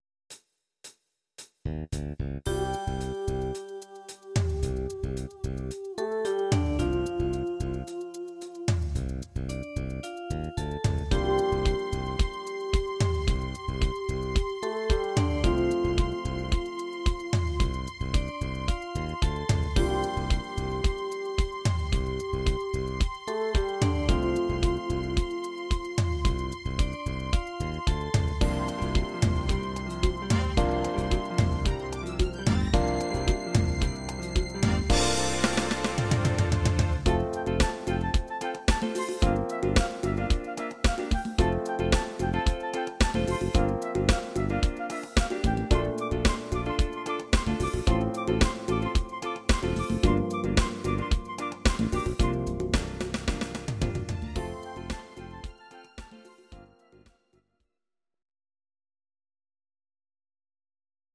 Audio Recordings based on Midi-files
Pop, Disco, 1970s